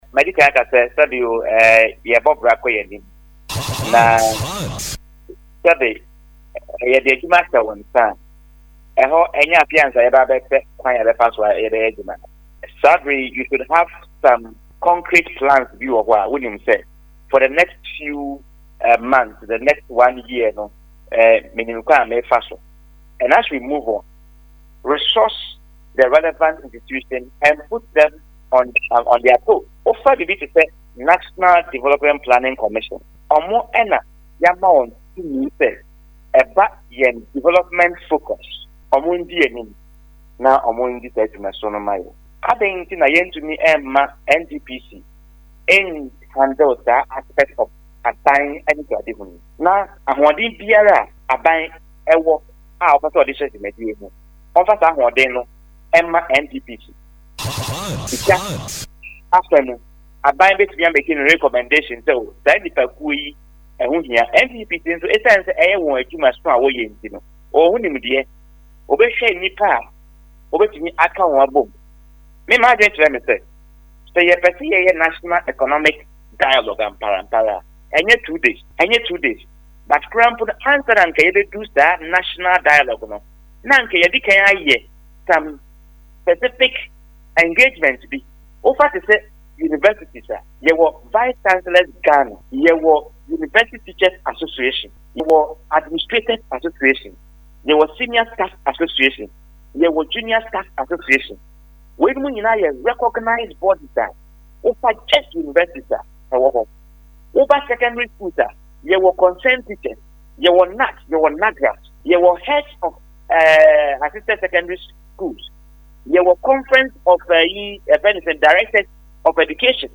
Speaking in a phone interview with Hot FM